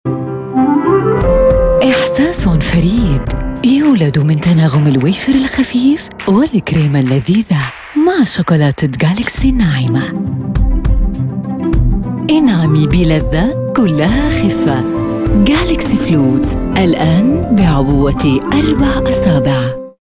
Sprechprobe: eLearning (Muttersprache):
If you're business requires an arabic female voice talent for recordings on a one time only or regular frequency, feel free to contact me.